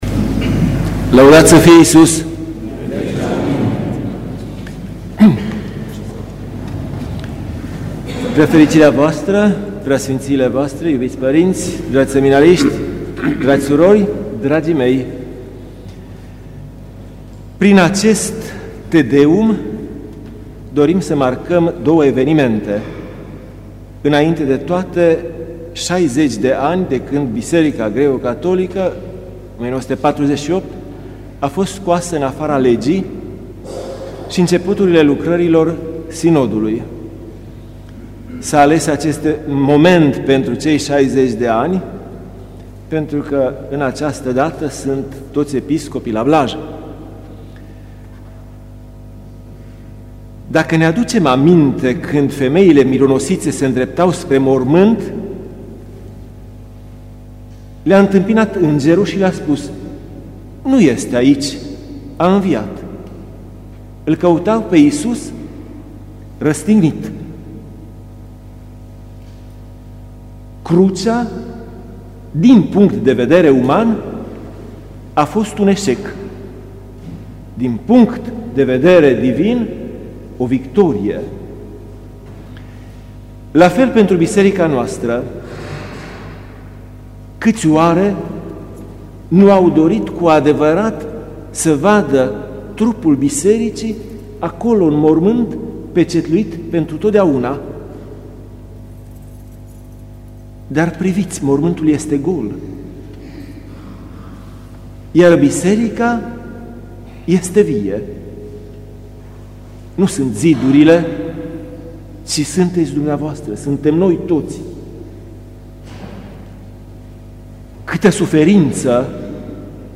Ascultă cuvântul integral al PS VIRGIL la Te Deum-ul din 21.10.2008